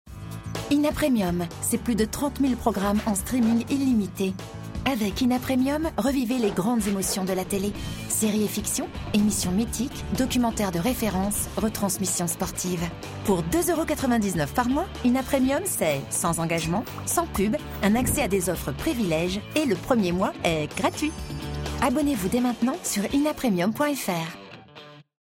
droite // solaire // promo
Pub-Tv-INA-Premium-droite-solaire-promo.mp3